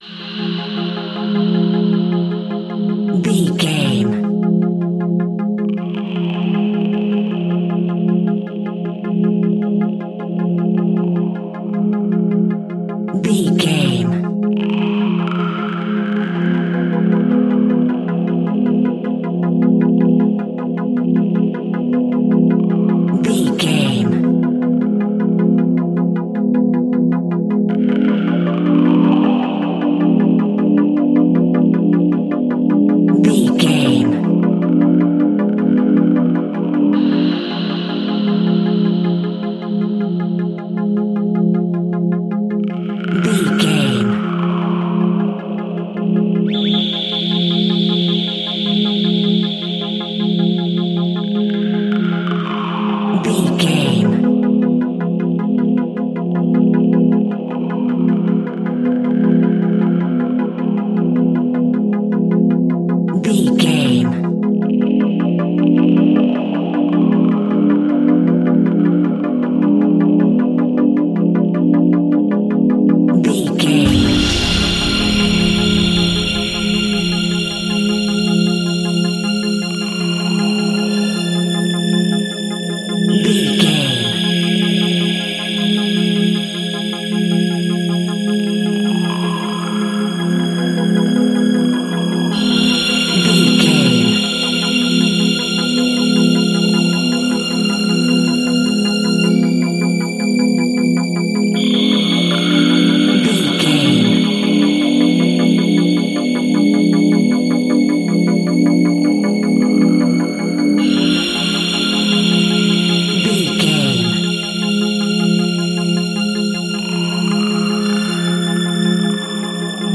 Haunted Mansion Music Sounds.
Aeolian/Minor
Slow
ominous
dark
eerie
synthesiser
strings
horror music
horror instrumentals